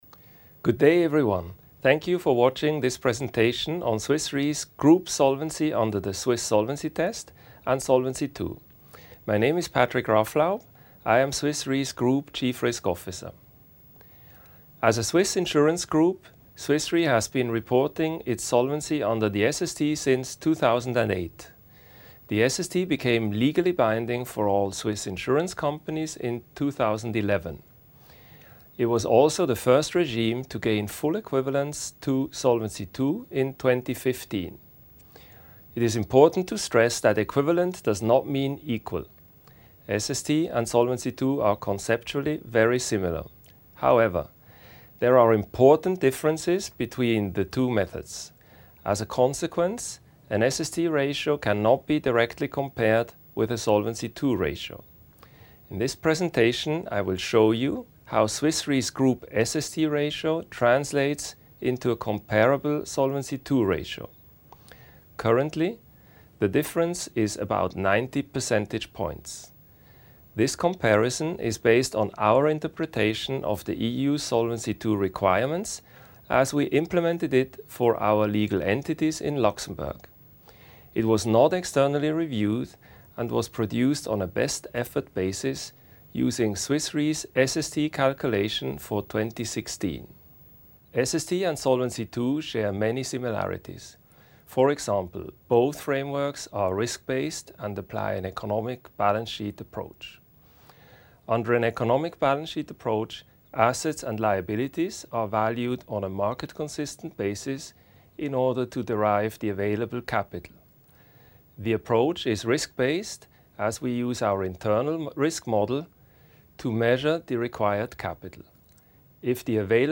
Audio of SST vs Solvency II Video Presentation
2016_sst_presentation_audio.mp3